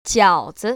[jiăo‧zi] 쟈오즈  ▶